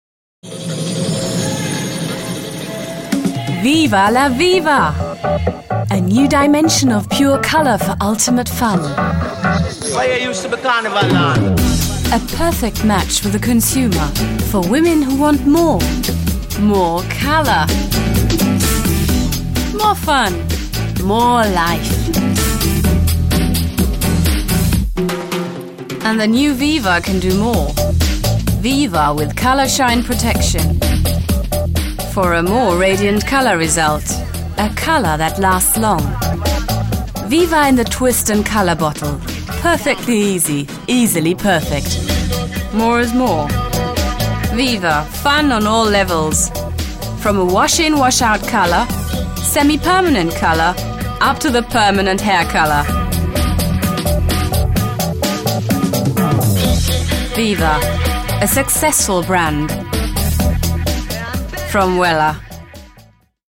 Sprecherin / Schauspielerin in Englisch und Deutsch
Kein Dialekt
Sprechprobe: Industrie (Muttersprache):